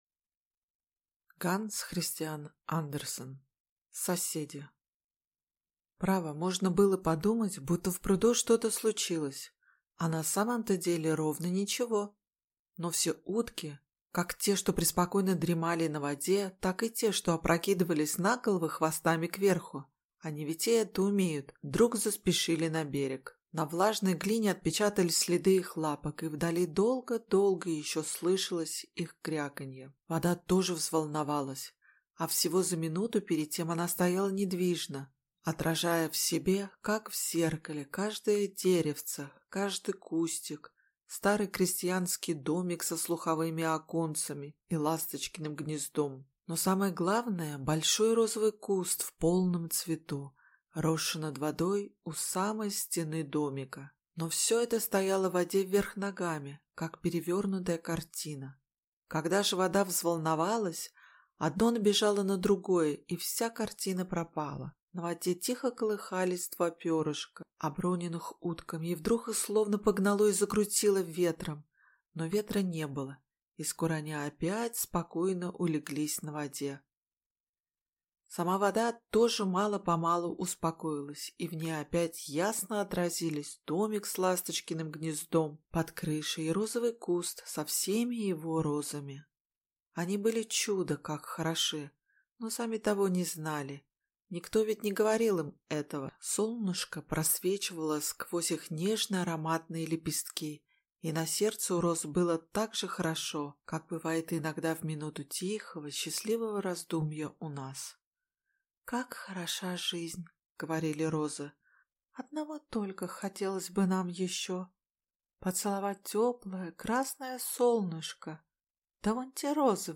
Аудиокнига Соседи | Библиотека аудиокниг